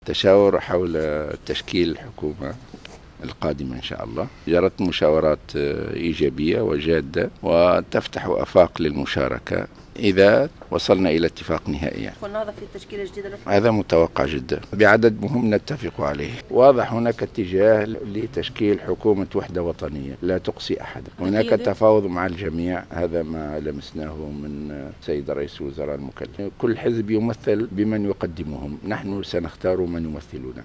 أكد رئيس حركة النهضة راشد الغنوشي في تصريح اعلامي عقب لقائه برئيس الحكومة المكلف الحبيب الصيد في اطار المشاورات لتشكيل الحكومة أن مشاركة الحركة في الحكومة القادمة أمر متوقع جدا على حد قوله.